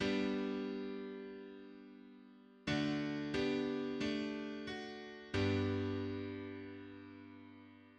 Double cadence
A double cadence is when, above ⑤, one puts the major 3rd and 5th, the 6th and 4th, the 5th and 4th, and then the major 3rd and 5th.